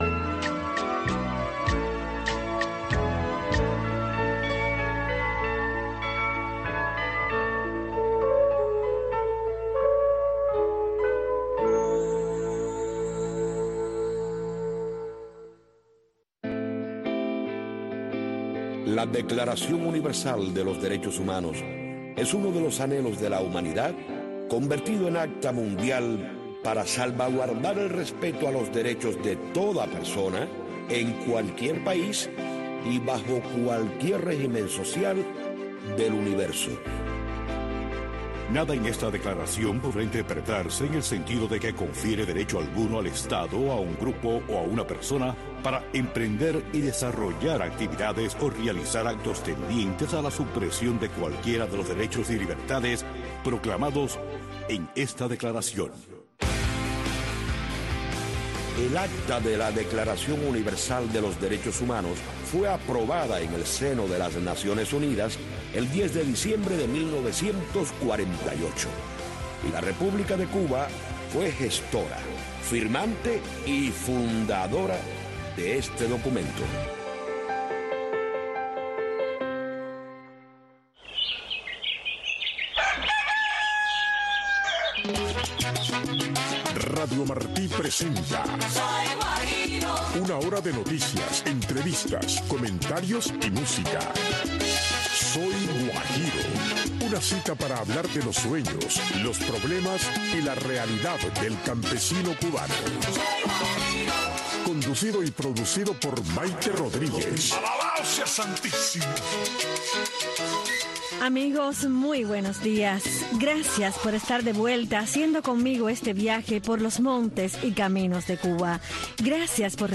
Soy Guajiro es un programa para los campesinos y guajiros con entrevistas, música y mucho más.